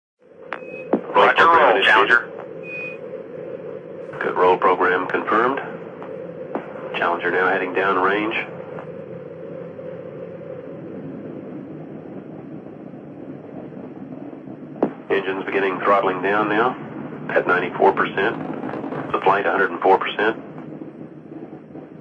Звуки рации
Переговоры астронавтов и космонавтов через рацию